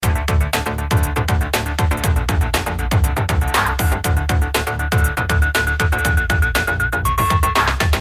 Wie nennt sich dieser Effekt und gibt es dafuer ein VST-Plugin? Klangbeispiel aus einem Lied